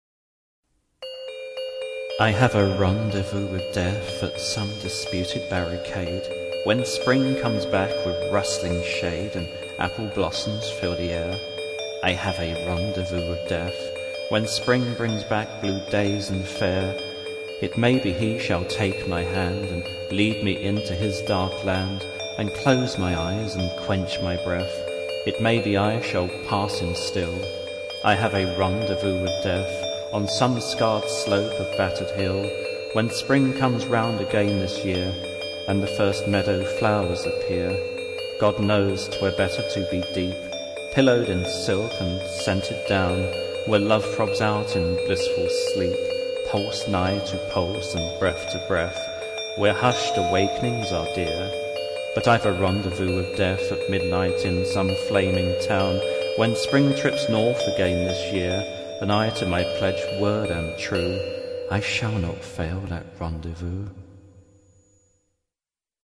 Recited with original music